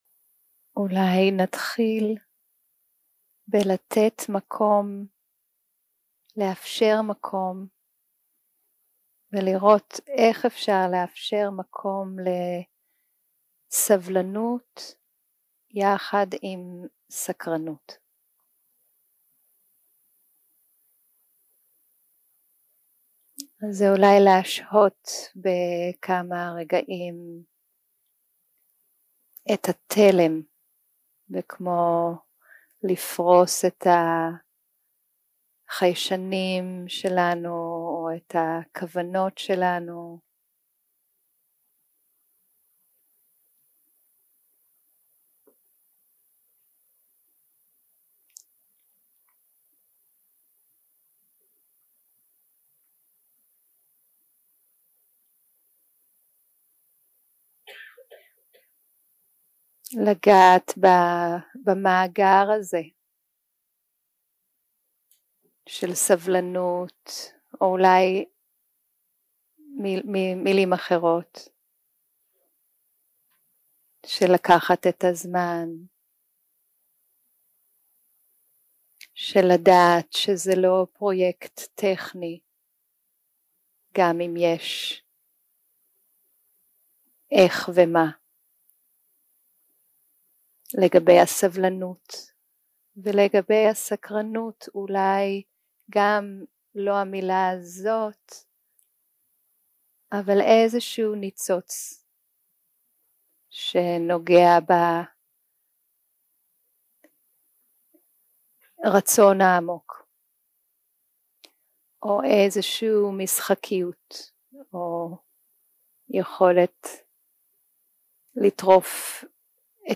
יום 2 – הקלטה 3 – צהריים – מדיטציה מונחית
Dharma type: Guided meditation שפת ההקלטה